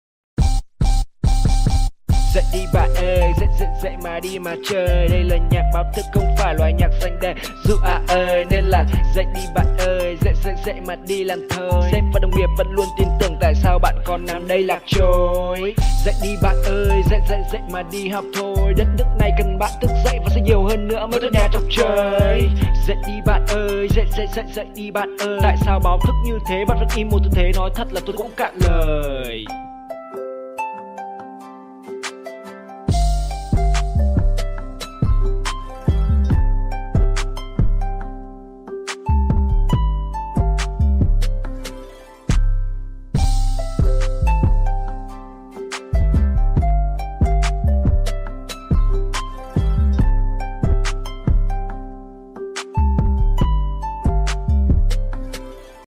Nhạc Chuông Báo Thức